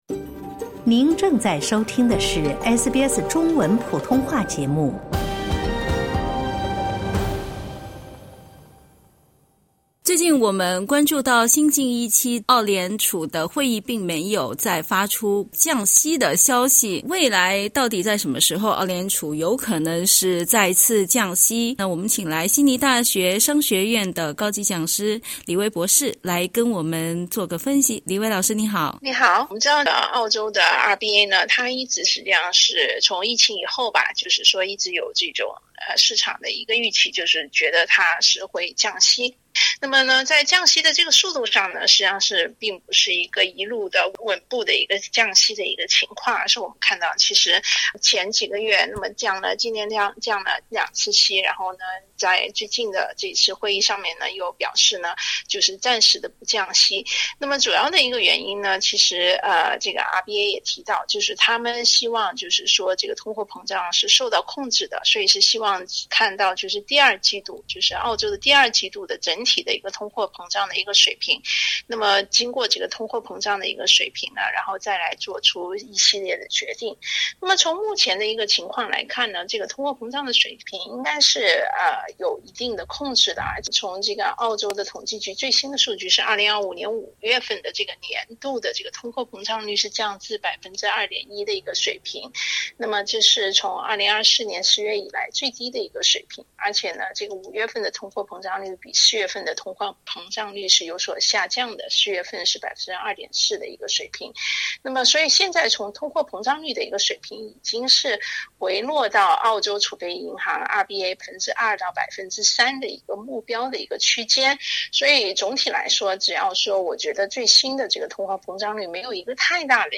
（点击音频收听详细采访） 最近一次的澳联储会议并没有降低现金利率，与此同时，分析人士称，有迹象表明，在高通胀时期困扰澳大利亚人的生活成本危机可能正在缓解。